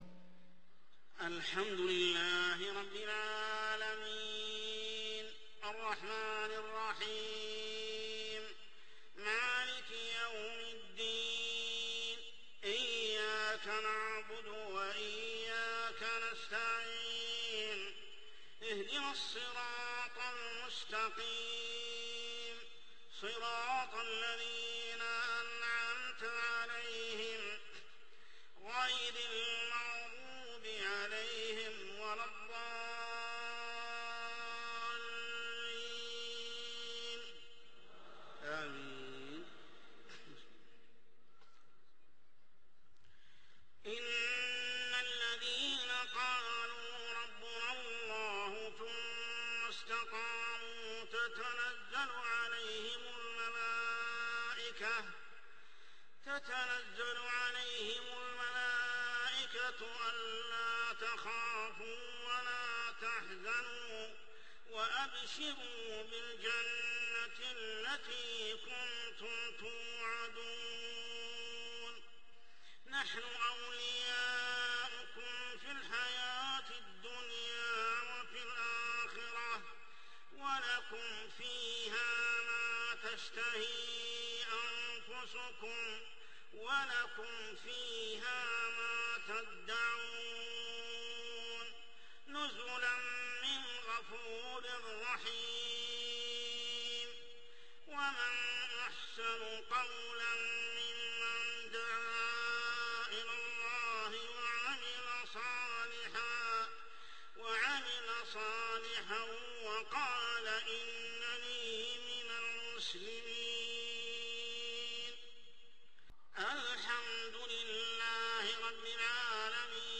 صلاة العشاء عام 1428هـ سورة فصلت 30-36 | Isha prayer Surah Fussilat > 1428 🕋 > الفروض - تلاوات الحرمين